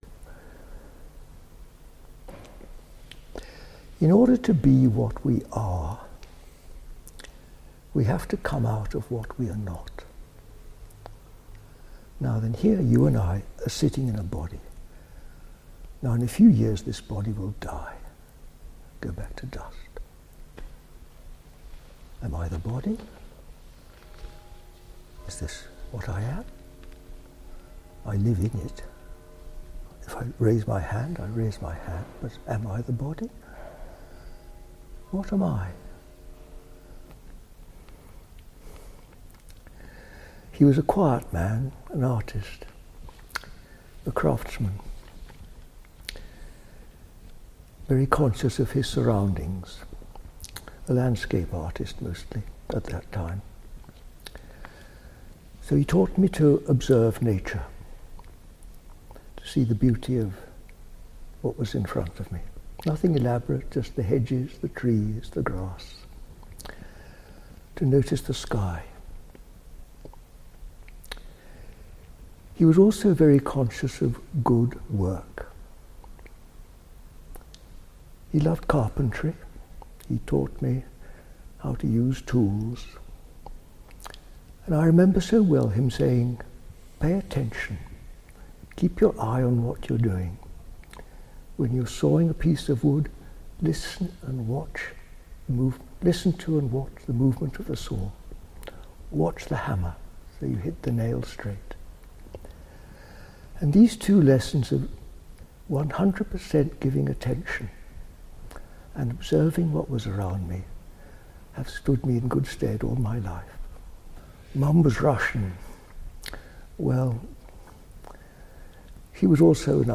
The Best Unintentional ASMR voice EVER re-edited to help you sleep in seconds